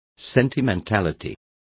Complete with pronunciation of the translation of sentimentality.